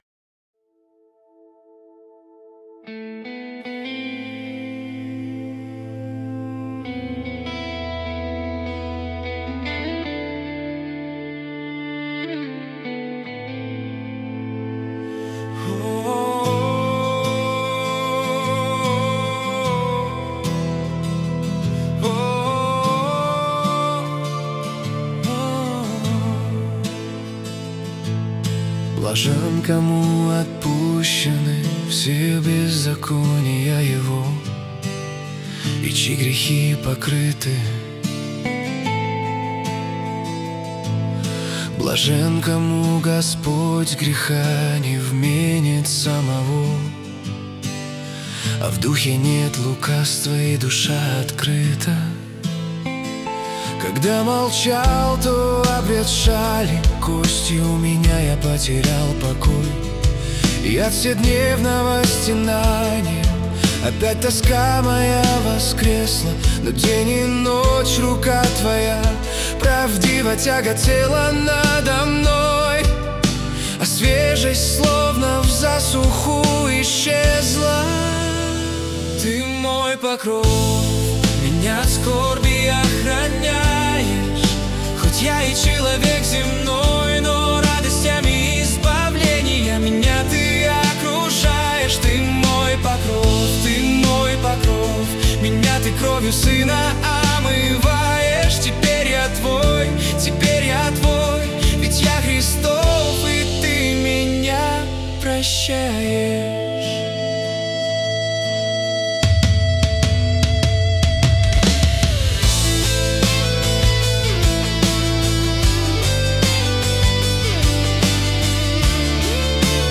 песня ai
218 просмотров 918 прослушиваний 93 скачивания BPM: 76